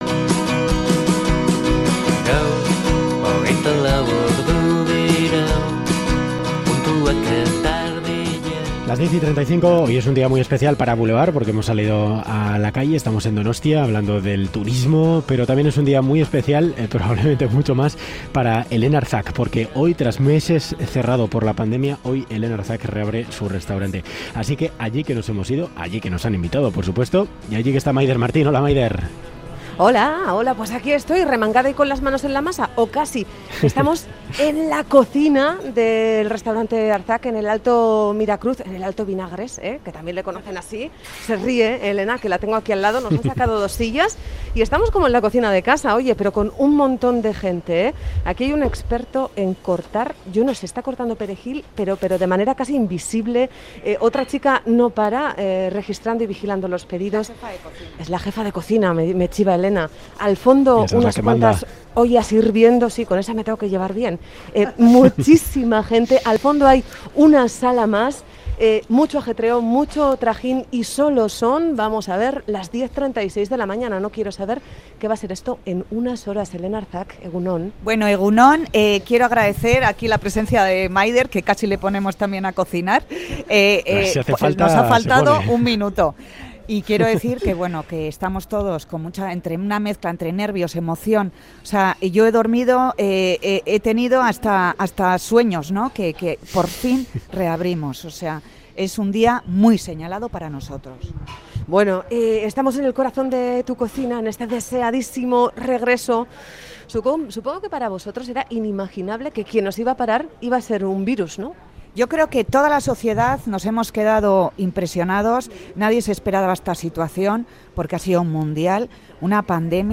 Audio: "Boulevard Informativo" de Radio Euskadi se ha colado en la cocina del Restaurante Arzak para vivir la reapertura en primera persona.